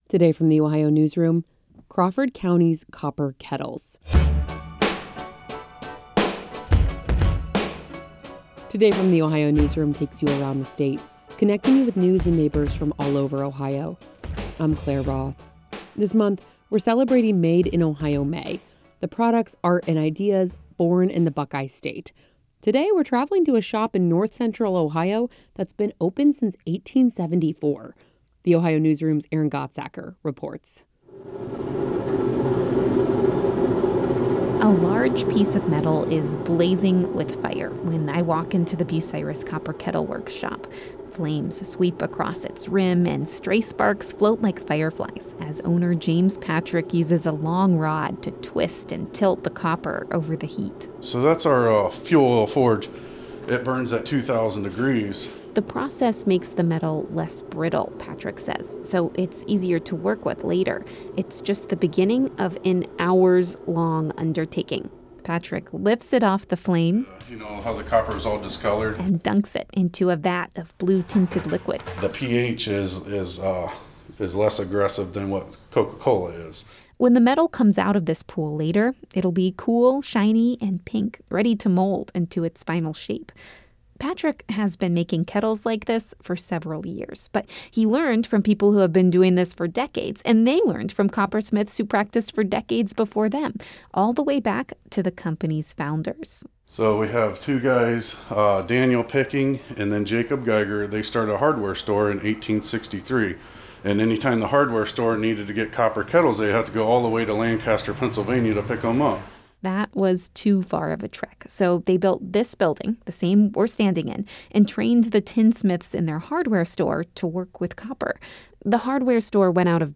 A large piece of metal blazes with fire inside the Bucyrus Copper Kettle Works shop in North Central Ohio.
He flips a switch and a rotating belt lifts and lowers a wooden hammer.